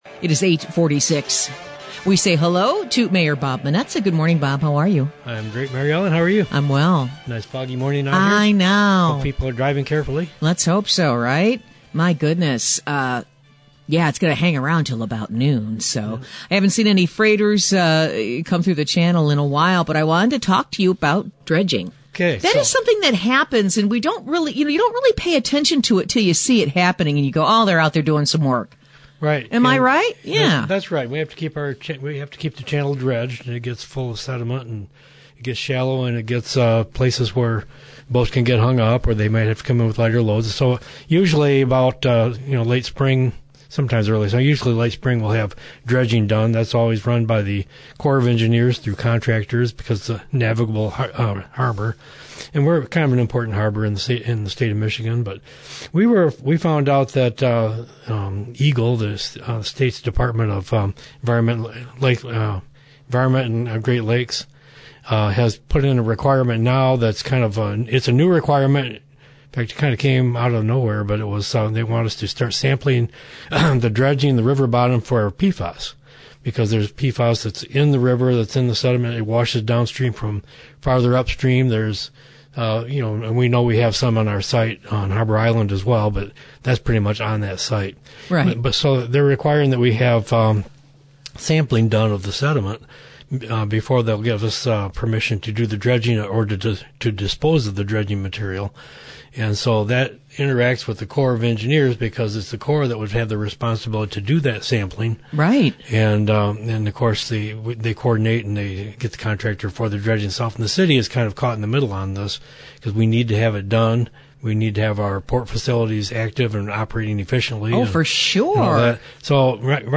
Dredging is going to be an issue this year. Find out why in the interview below.